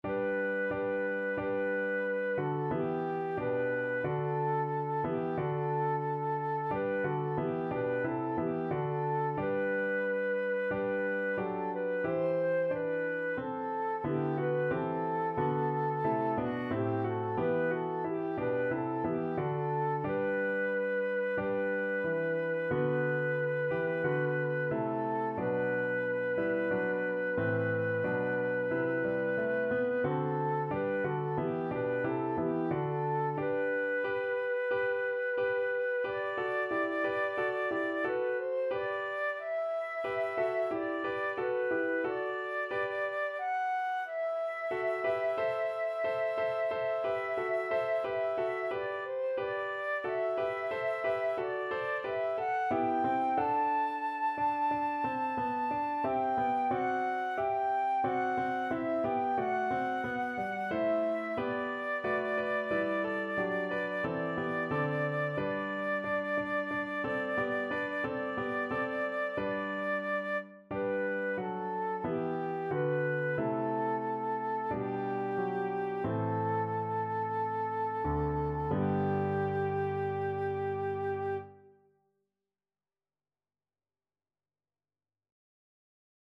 Flute
G major (Sounding Pitch) (View more G major Music for Flute )
4/4 (View more 4/4 Music)
Classical (View more Classical Flute Music)